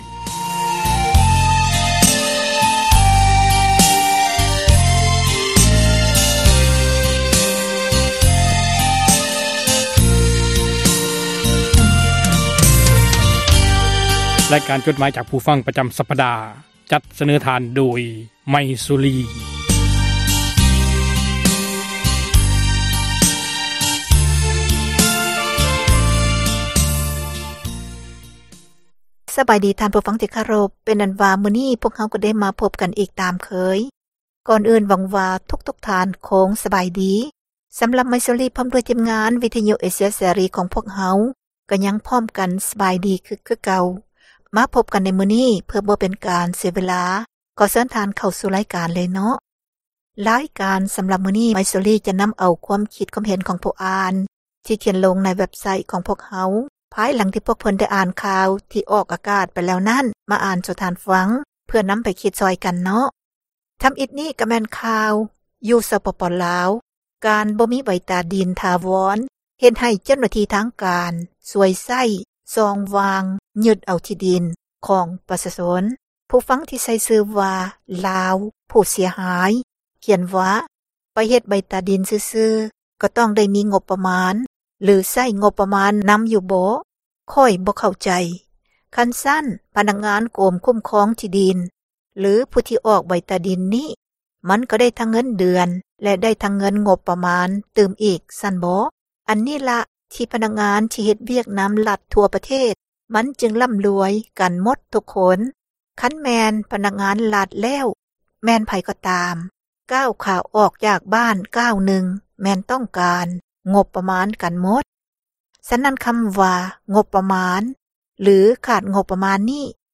ໝາຍເຫດ: ຄວາມຄິດເຫັນ ຂອງທ່ານຜູ່ອ່ານ ທີ່ສະແດງອອກ ໃນເວັບໄຊທ໌ ແລະ ເຟສບຸກຄ໌ ຂອງວິທຍຸ ເອເຊັຽ ເສຣີ ນັ້ນ, ພວກເຮົາ ທິມງານ ວິທຍຸ ເອເຊັຽ ເສຣີ ໃຫ້ຄວາມສຳຄັນ ແລະ ຂອບໃຈ ນຳທຸກໆຖ້ອຍຄຳ, ແລະ ມີໜ້າທີ່ ນຳມາອ່ານໃຫ້ທ່ານ ໄດ້ຮັບຟັງກັນ ແລະ ບໍ່ໄດ້ເສກສັນປັ້ນແຕ່ງໃດໆ, ມີພຽງແຕ່ ປ່ຽນຄຳສັພ ທີ່ບໍ່ສຸພາບ ໃຫ້ເບົາລົງ ເທົ່ານັ້ນ. ດັ່ງນັ້ນ ຂໍໃຫ້ທ່ານຜູ່ຟັງ ຈົ່ງຕັດສິນໃຈເອົາເອງ ວ່າ ຄວາມຄຶດຄວາມເຫັນນັ້ນ ເປັນໜ້າເຊື່ອຖື ແລະ ຄວາມຈິງ ຫຼາຍໜ້ອຍປານໃດ.